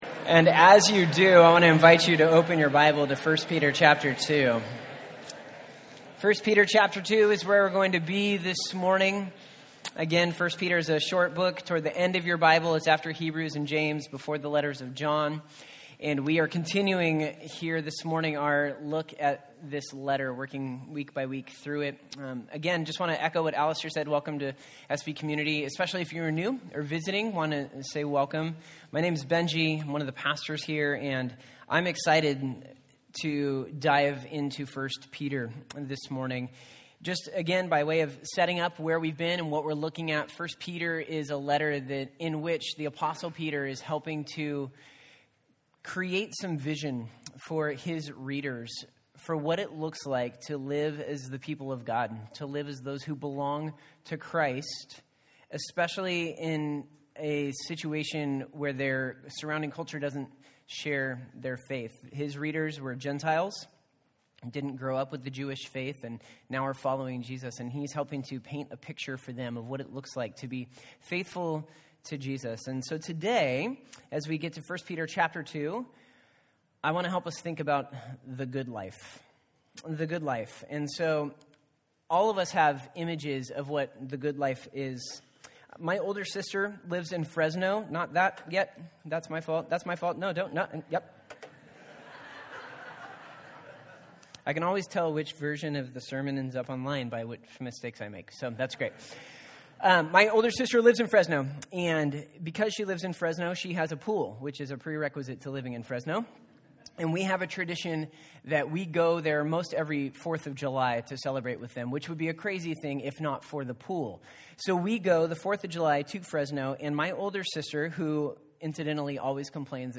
1 Peter 2:11-25 Service Type: Sunday Topics